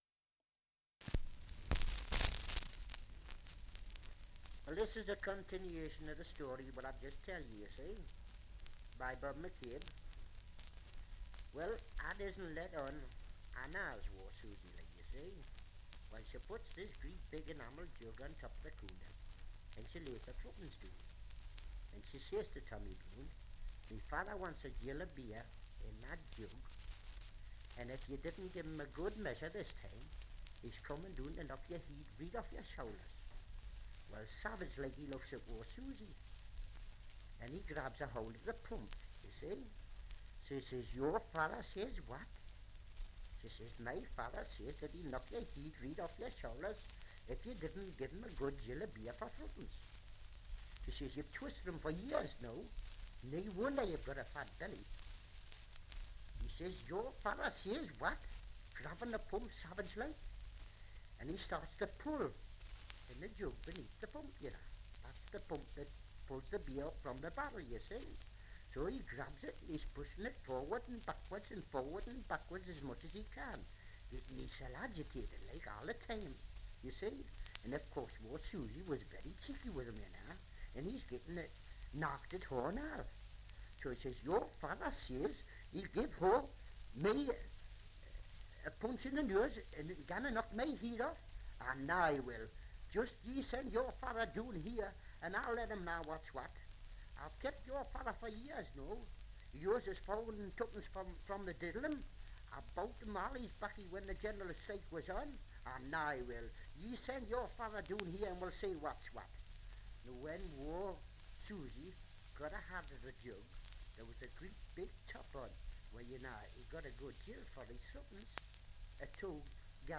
1 - Dialect recording in Newcastle upon Tyne
78 r.p.m., cellulose nitrate on aluminium